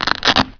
menu_abort.wav